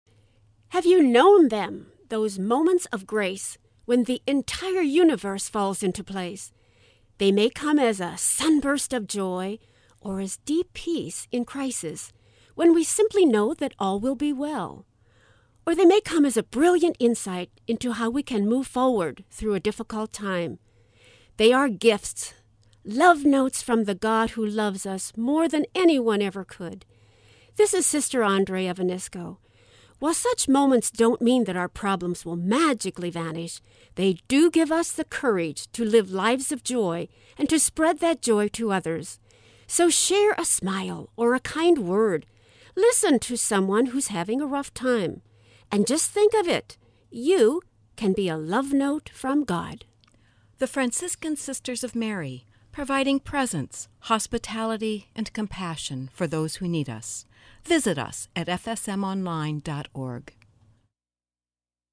Each month, FSM share a one-minute message of hope, joy, grace or encouragement on the St. Louis classical music radio station.